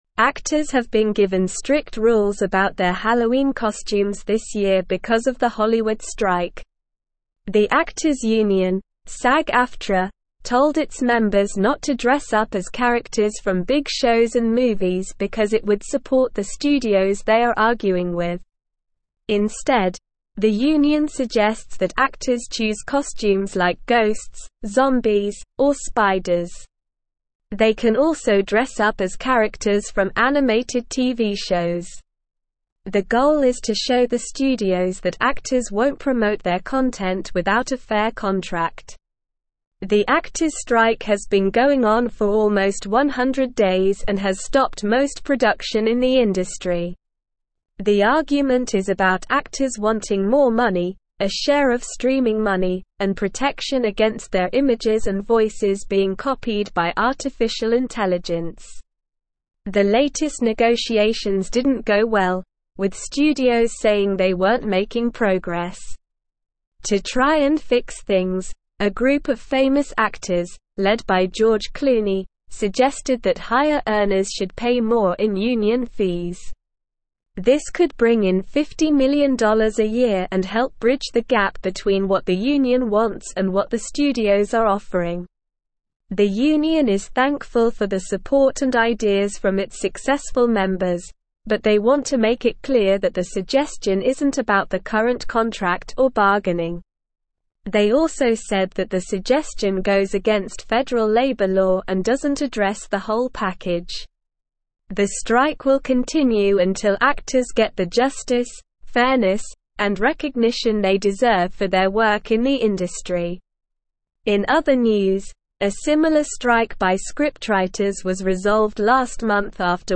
Slow
English-Newsroom-Upper-Intermediate-SLOW-Reading-Hollywood-Actors-Given-Halloween-Costume-Rules-Amid-Strike.mp3